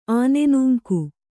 ♪ ānenūŋku